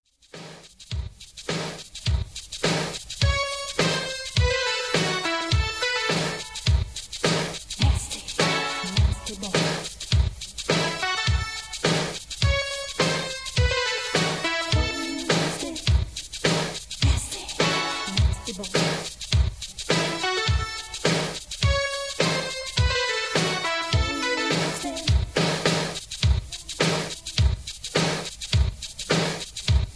(Version-3, Key-Fm) Karaoke MP3 Backing Tracks
Just Plain & Simply "GREAT MUSIC" (No Lyrics).